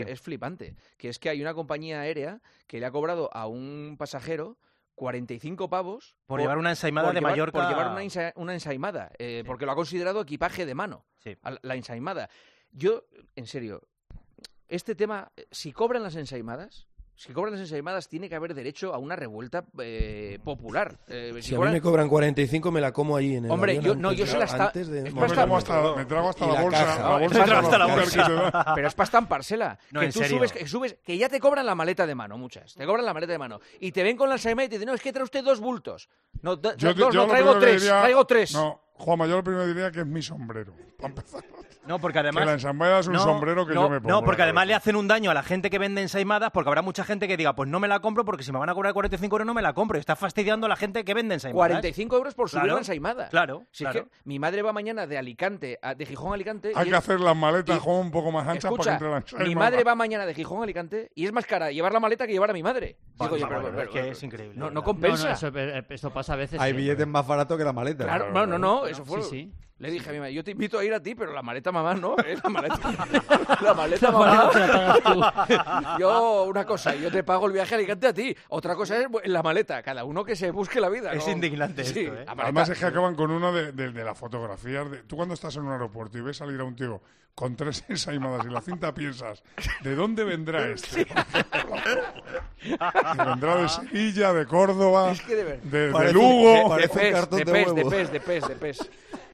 El presentador de 'El Partidazo de COPE' comenta con el resto del 'Tiempo de Opinión' la noticia sobre una aerolínea que ha tomado esta decisión